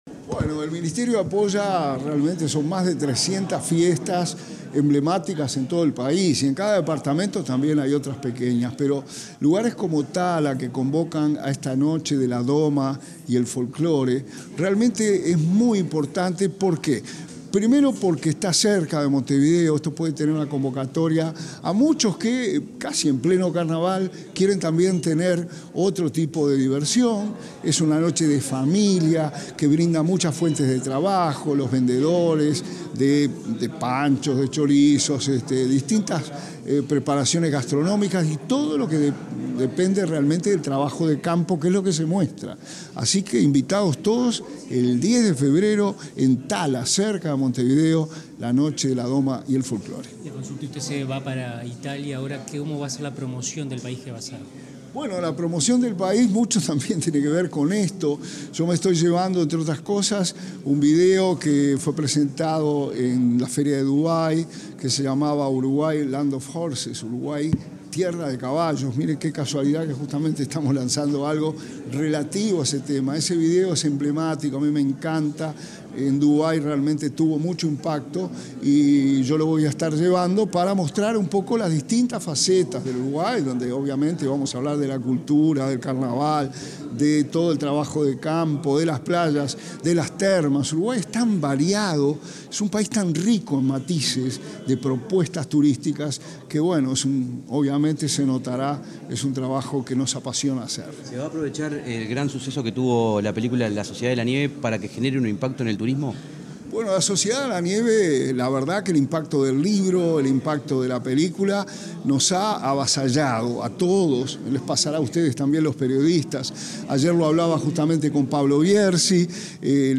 Declaraciones a la prensa del ministro interino de Turismo, Remo Monzeglio
Tras el evento, el ministro interino de Turismo, Remo Monzeglio, efectuó declaraciones a la prensa.